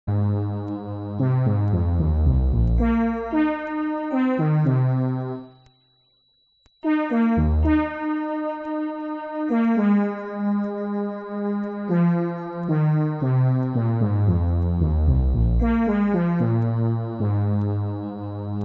allow its beautiful tones to comfort and bring you peace